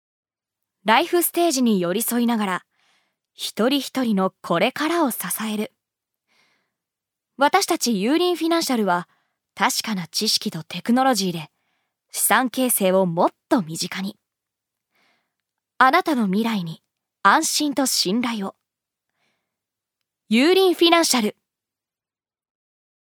ジュニア：女性
ナレーション３